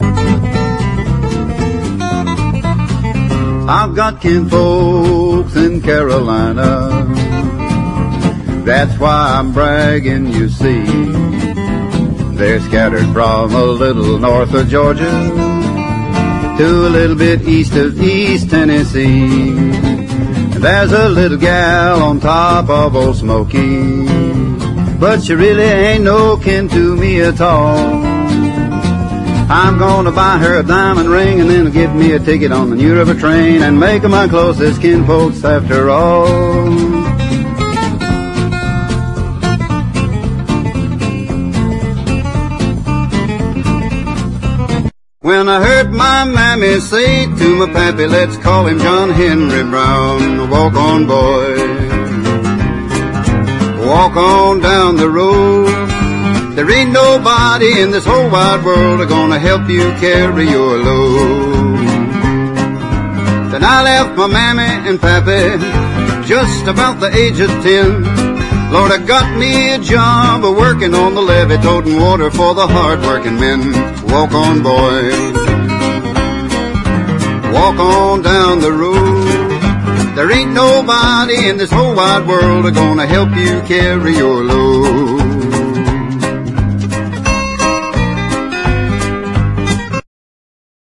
EASY LISTENING / EASY LISTENING / BIG BAND / TWIST